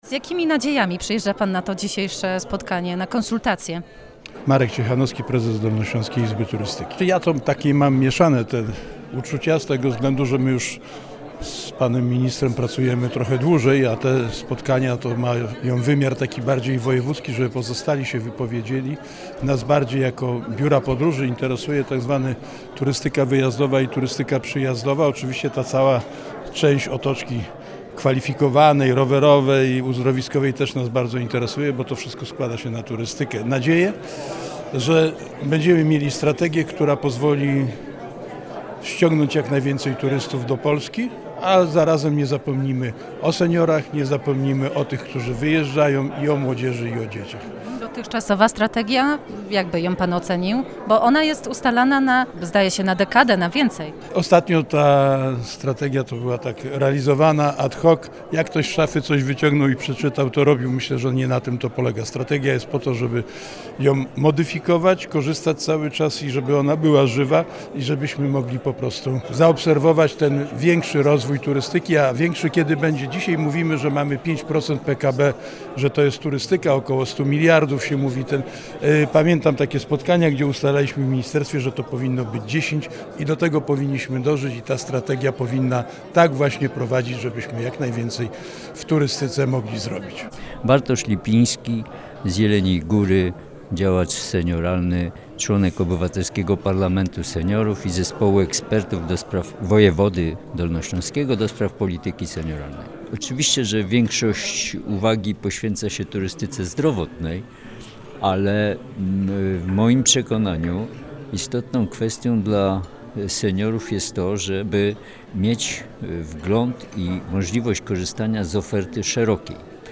Ważnym elementem spotkania branżowego były konsultacje z samorządowcami oraz pracownikami branży turystycznej z Dolnego Śląska. Zapytaliśmy uczestników o ocenę pomysłu na strategię rozwoju turystyki.
05_sonda.mp3